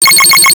Commodore PET startup sound (again)
Conveniently, all of these steps have the same duration (0.01848 s).
The end result, cobbled together by many calls to sox, sounds like this: Commodore PET Synthesized Startup Chime: mp3 It sounds not too bad. It doesn’t have the fade-in effect caused by the PET’s power supply coming on, but it has the right character.
Yes, it’s all square waves If you want the sound for a phone notification: pet_chime.ogg is for Android; pet_chime.m4r is for iOS.
pet_chime.ogg